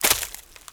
HauntedBloodlines/STEPS Leaves, Walk 20.wav at 545eca8660d2c2e22b6407fd85aed6f5aa47d605
STEPS Leaves, Walk 20.wav